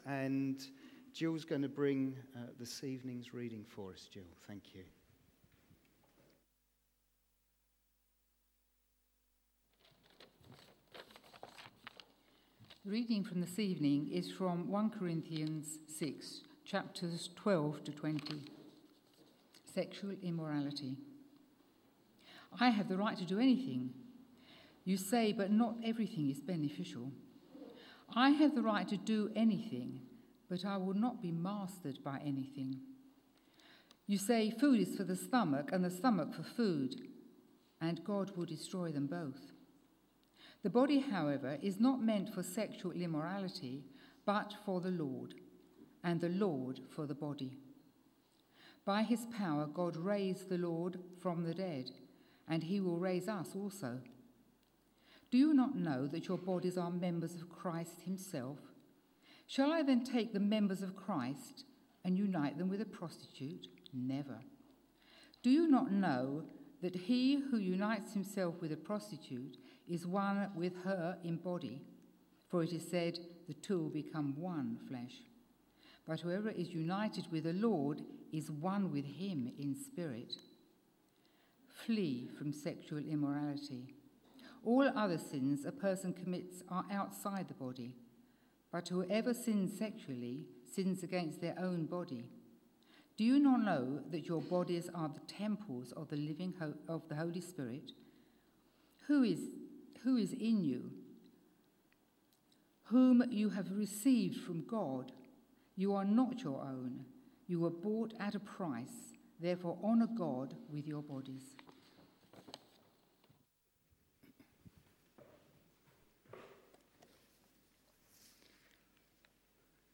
A message from the series "Devotion to God."